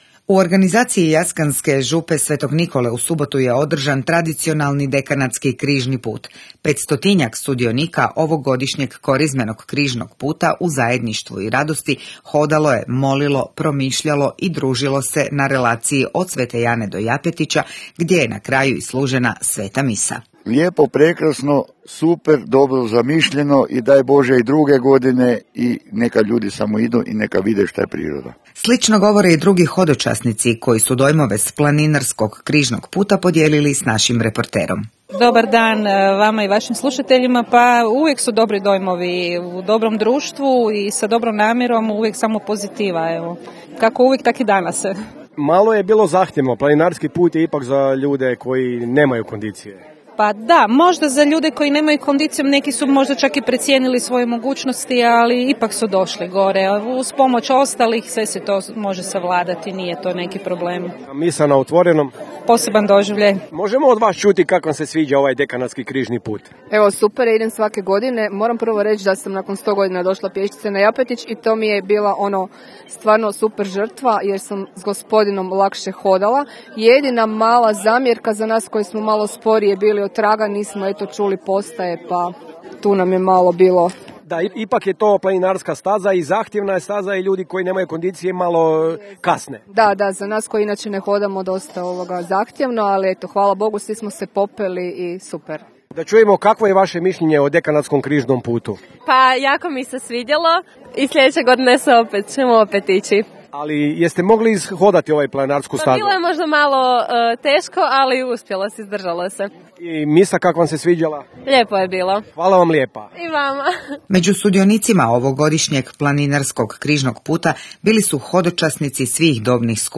Dok slušate audio izvještaj, pogledajte fotografije.